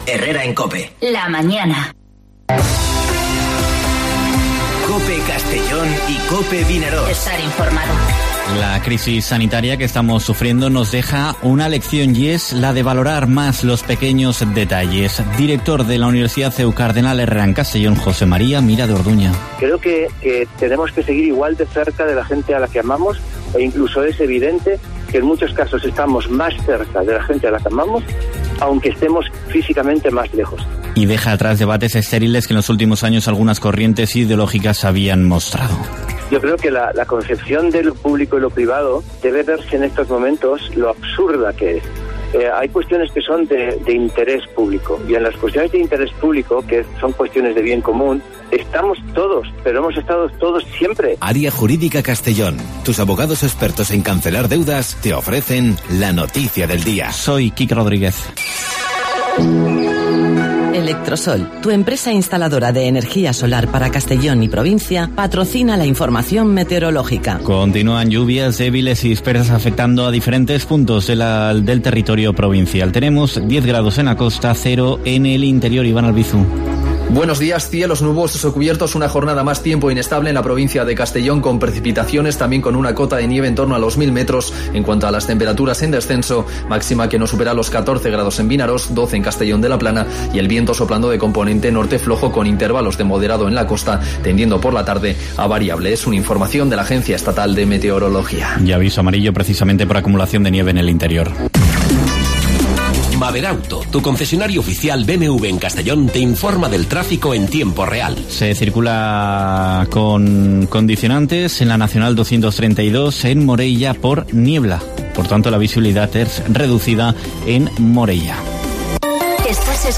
Informativo Herrera en COPE en la provincia de Castellón (25/03/2020)